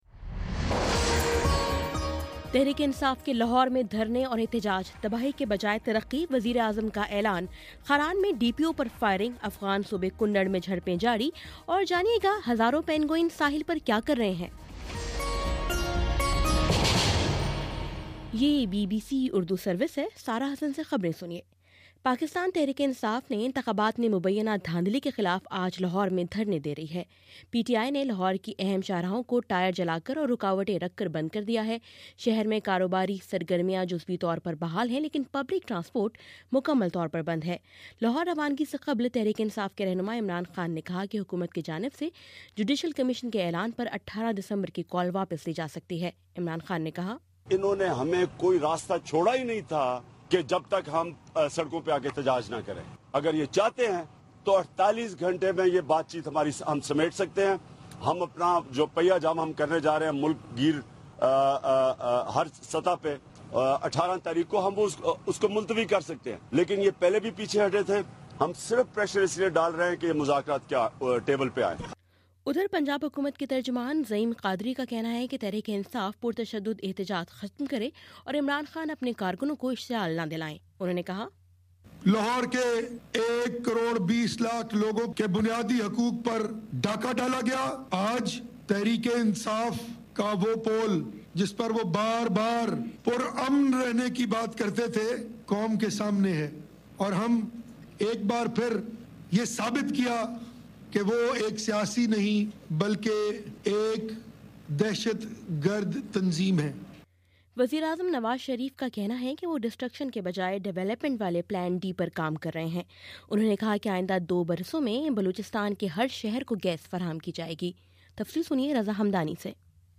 دسمبر15: شام چھ بجے کا نیوز بُلیٹن